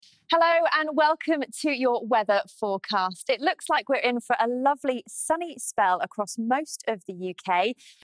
Join our delightful presenter for sound effects free download